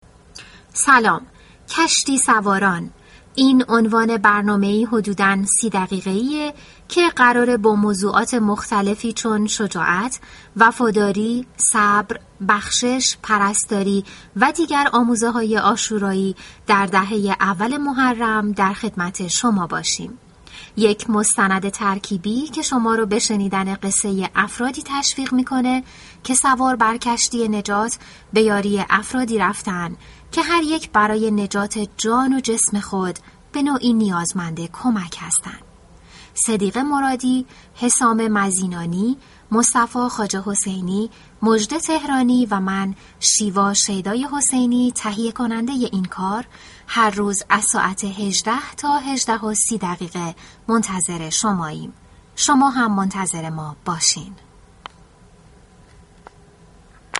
قالب برنامه به صورتِ مستند- روایی طراحی شده است كه در بینِ آن از نشانه‌های كمكی دیگری جهتِ فهم بیشتر و البته زیبایی سمعی استفاده شده است. موضوع كه در هر برنامه بنا به موقیعتِ شغلی تفاوت دارد، به روایتِ قصه‌ی زندگی‌اش می‌پردازد كه در عینِ حال از فضای زندگیِ كاری و شخصی اش نیز استفاده می‌شود.